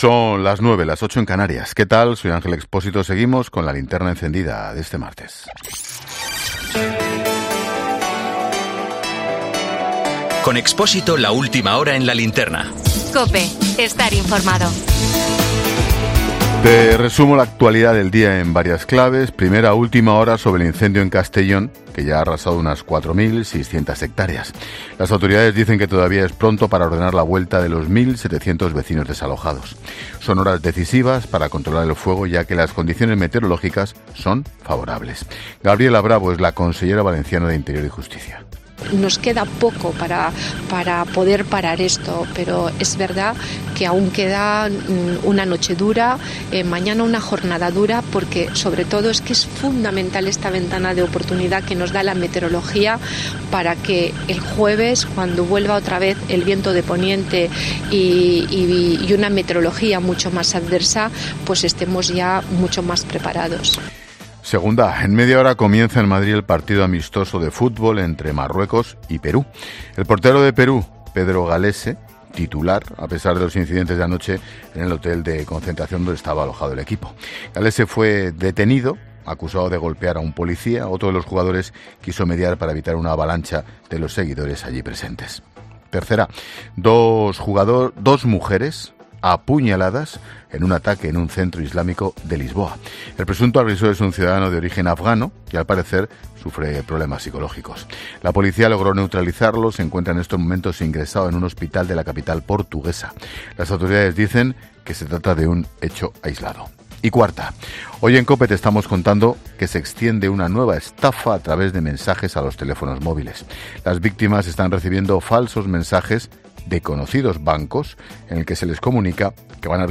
Actualización de noticias en La Linterna de COPE.